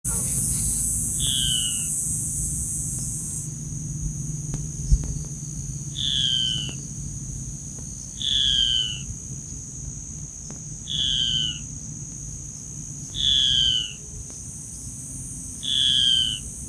Ocellated Crake (Rufirallus schomburgkii)
Life Stage: Adult
Detailed location: Reserva Natural Silvestre Parque Federal Campo San Juan
Condition: Wild
Certainty: Recorded vocal
Burrito-ocelado.mp3